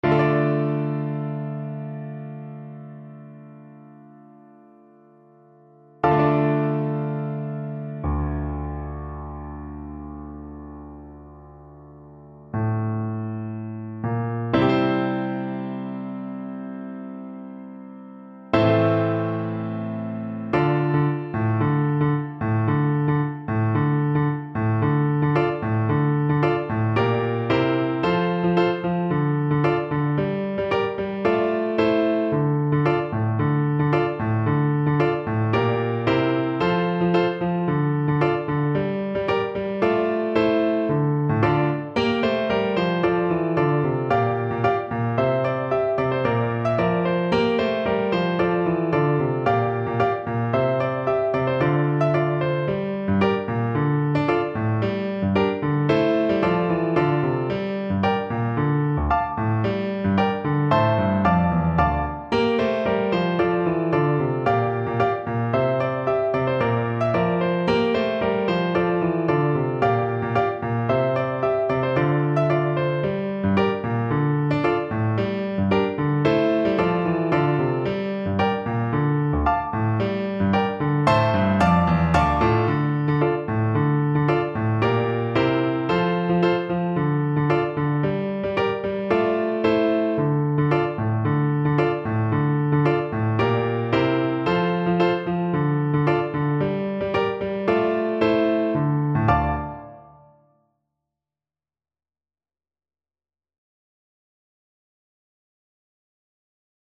Play (or use space bar on your keyboard) Pause Music Playalong - Piano Accompaniment Playalong Band Accompaniment not yet available transpose reset tempo print settings full screen
Flute
2/4 (View more 2/4 Music)
F major (Sounding Pitch) (View more F major Music for Flute )
Slow